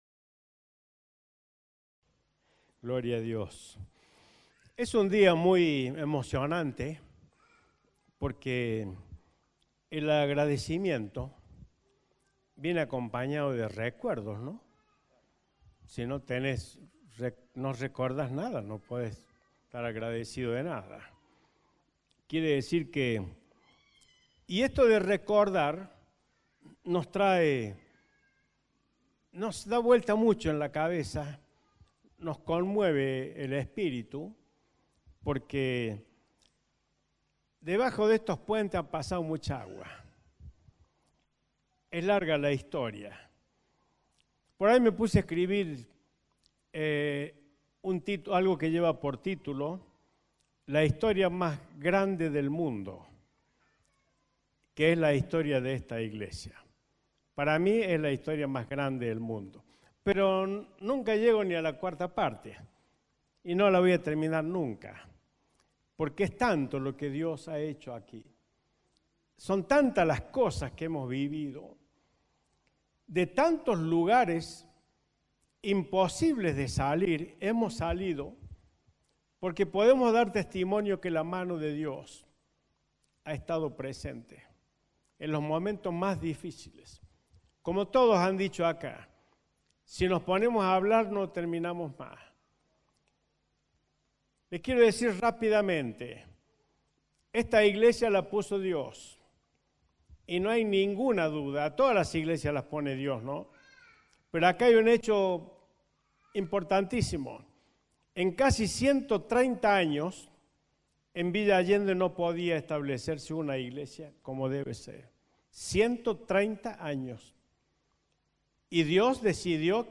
Compartimos la Prédica del Domingo especial “Acción de Gracias”.